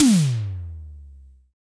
Index of /90_sSampleCDs/Roland L-CDX-01/KIT_Drum Kits 5/KIT_Tiny Kit
TOM E.TOM 2.wav